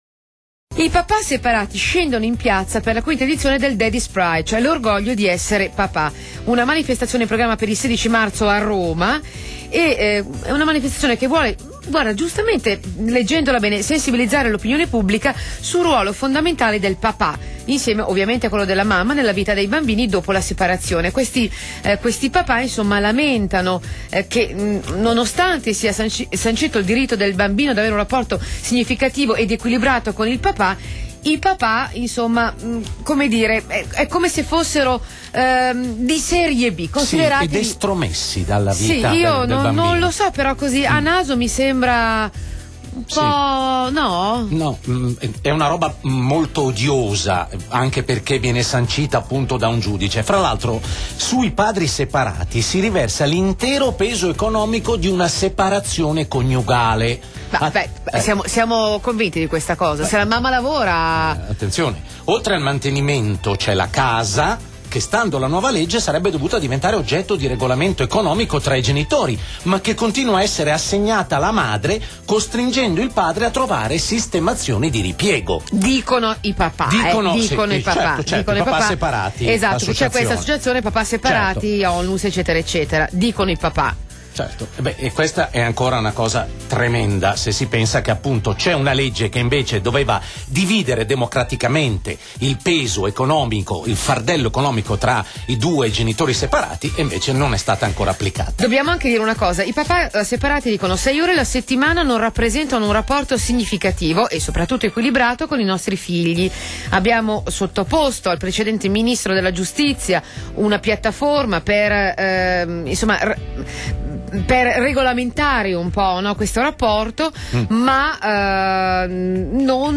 L'Associazione Papà Separati Lombardia, in diretta telefonica su RTL 102.5, alle 17,05 del 21.02.2008